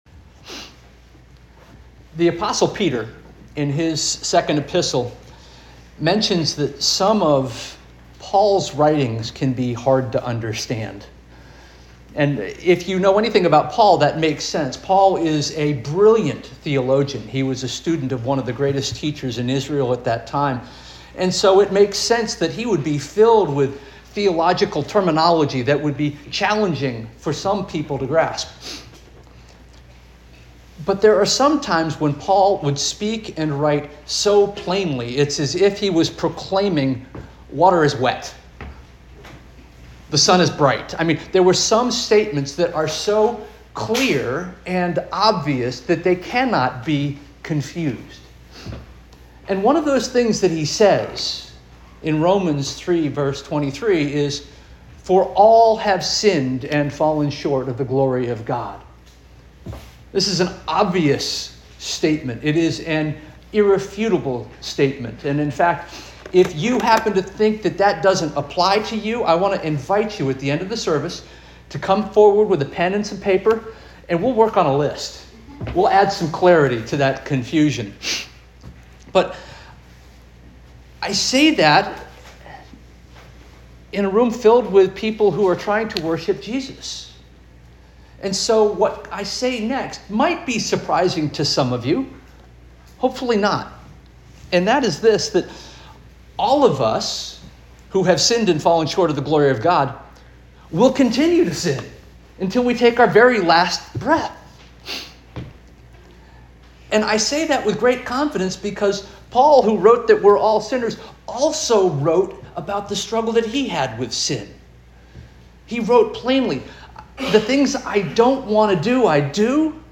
October 19 2025 Sermon - First Union African Baptist Church